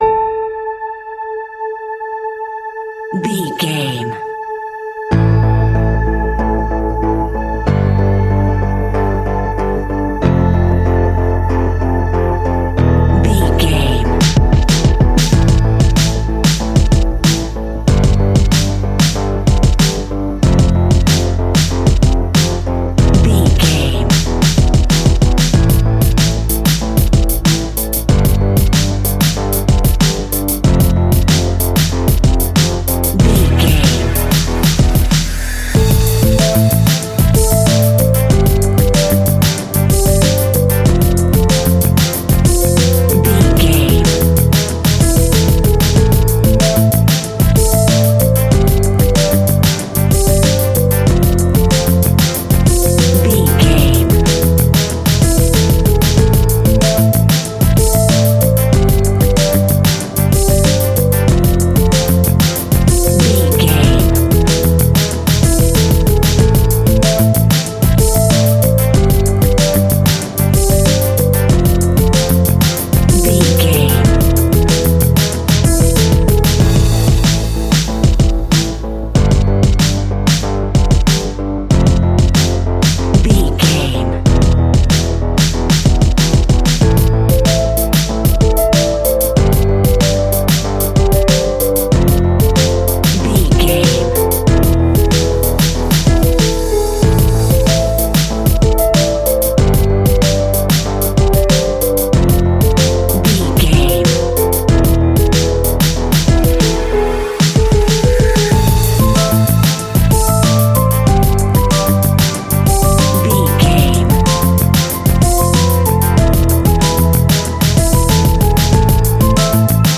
Epic / Action
Aeolian/Minor
hip hop
hip hop instrumentals
downtempo
hip hop synths
synth lead
synth bass
synth drums
turntables